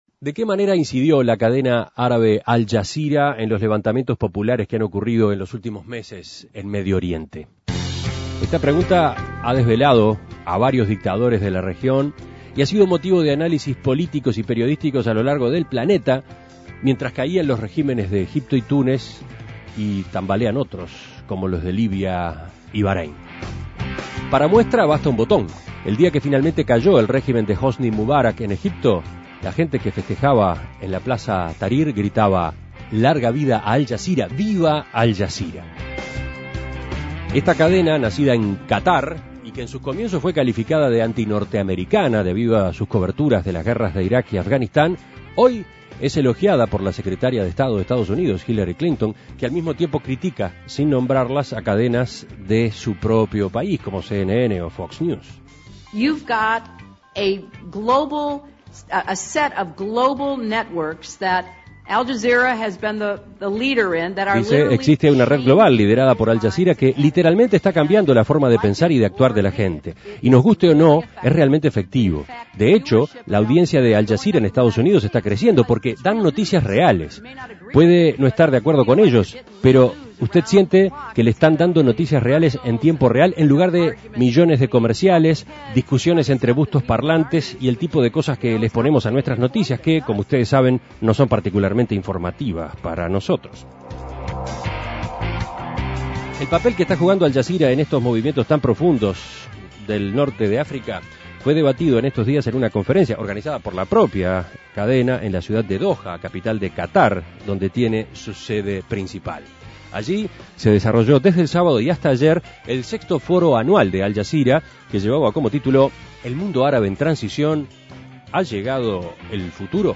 Escuche el contacto con Mario Lubetkin, director de la Agencia de Noticias IPS y colaborador de En Perspectiva.